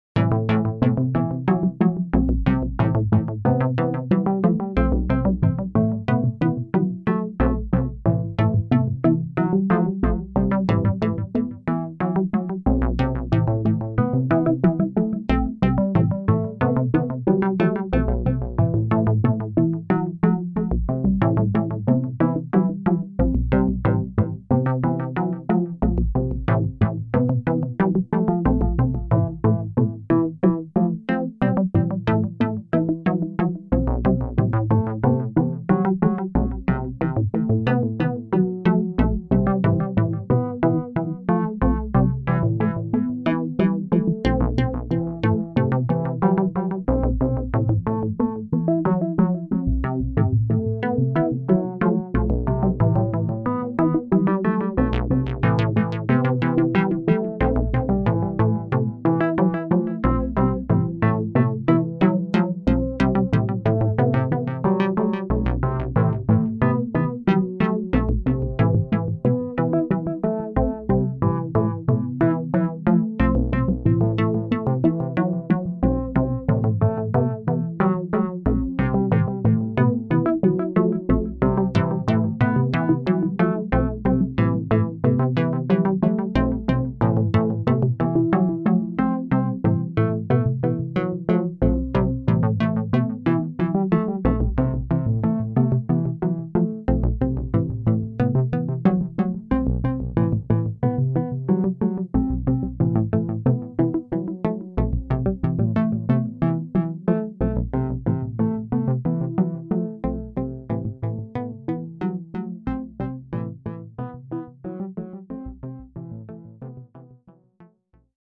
The next two links/descriptions are UEG specific demos created by Robert Rich.
Two UEGs clocked with an MOTM lfo. A divider/mux is making extra triggers for syncopation, and two additional lfos are shifting the PWM and filter cutoffs of two simple voices (VCO -> 440 LP filter -> VCA). There is also an echo to add rhythmic complexity.